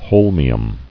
[hol·mi·um]